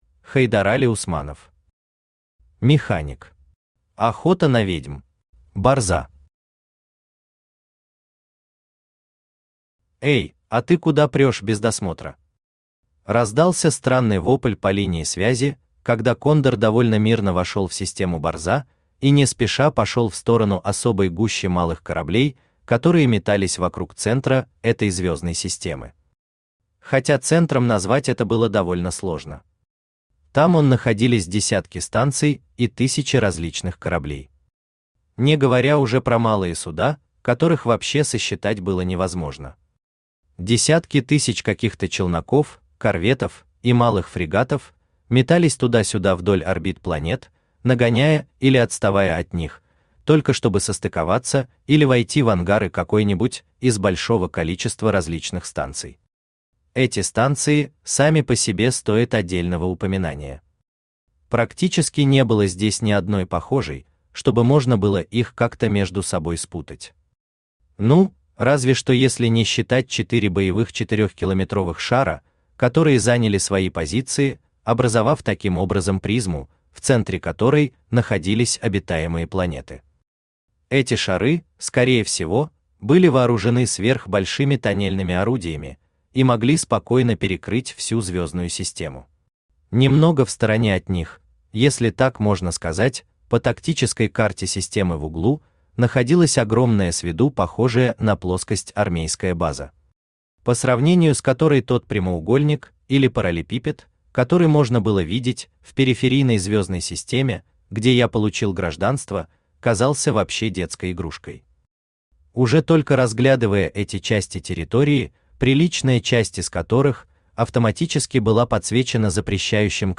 Аудиокнига Механик. Охота на ведьм | Библиотека аудиокниг
Охота на ведьм Автор Хайдарали Усманов Читает аудиокнигу Авточтец ЛитРес.